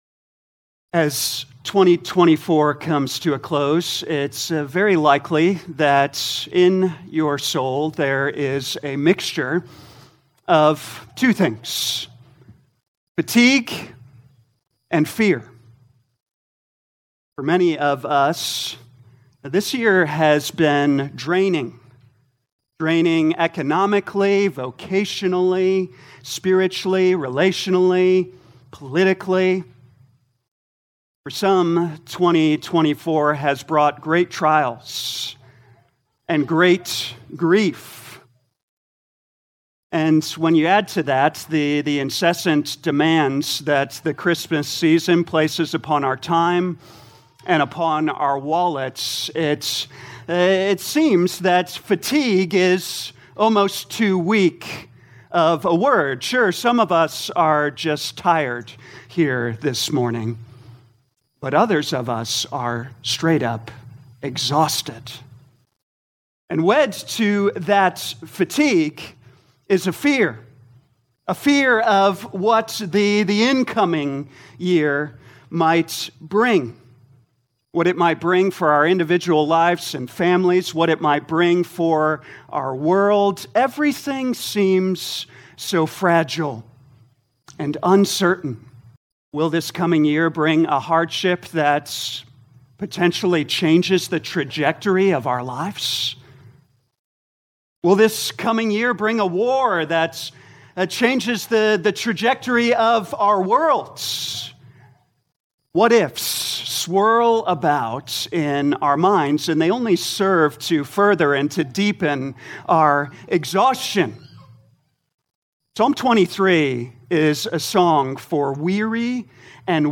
2024 Psalms Morning Service Download: Audio Notes Bulletin All sermons are copyright by this church or the speaker indicated.